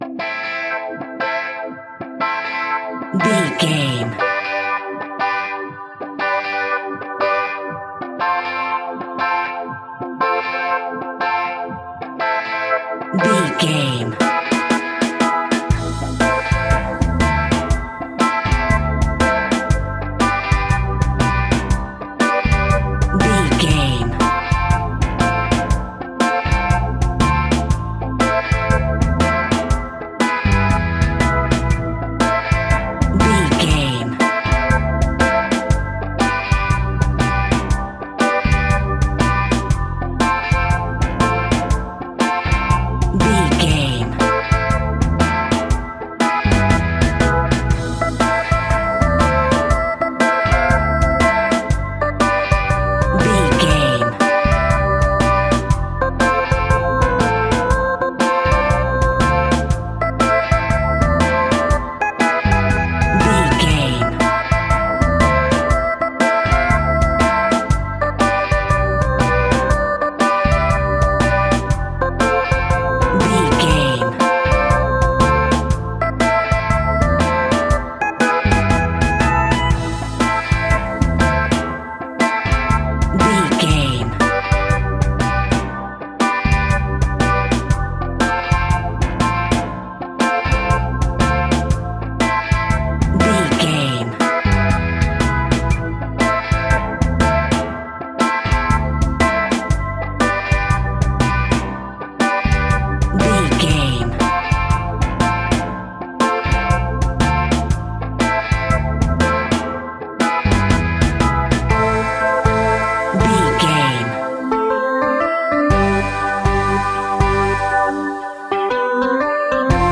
Aeolian/Minor
Slow
dub
reggae instrumentals
laid back
chilled
off beat
drums
skank guitar
hammond organ
percussion
horns